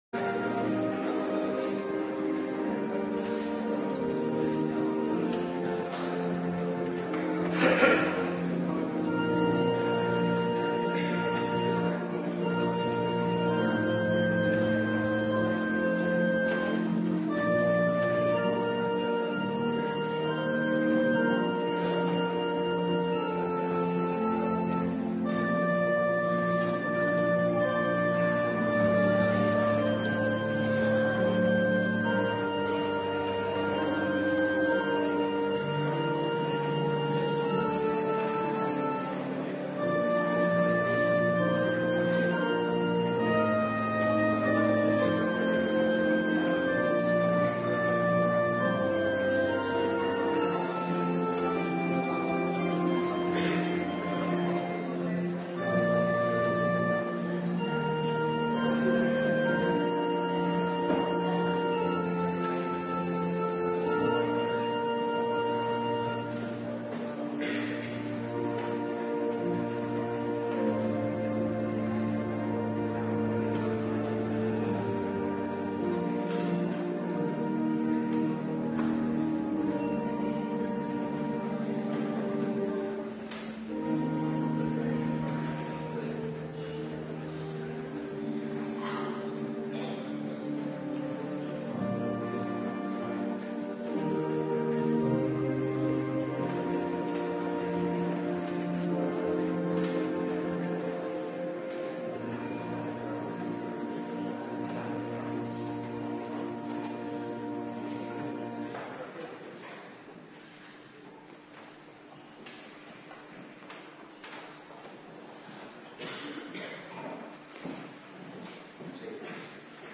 Avonddienst Nabetrachting Heilig Avondmaal
Locatie: Hervormde Gemeente Waarder